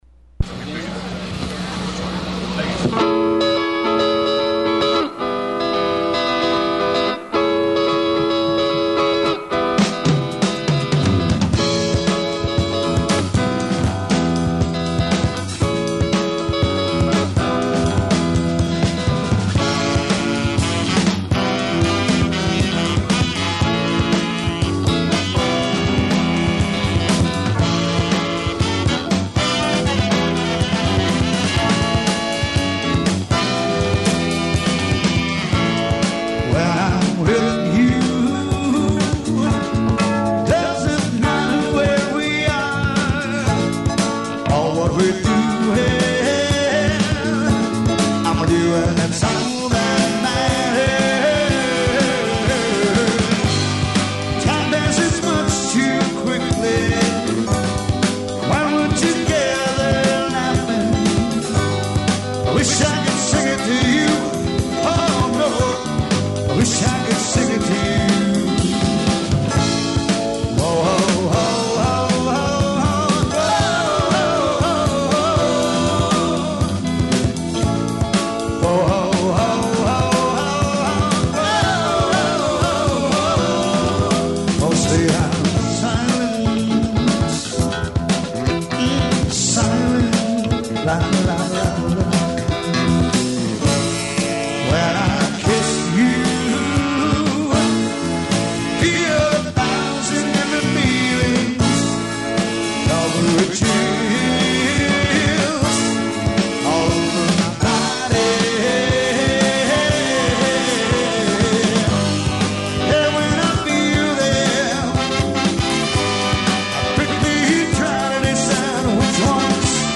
8 piece group
guitar, bass, keyboards, drums, sax, trombone, and trumpet.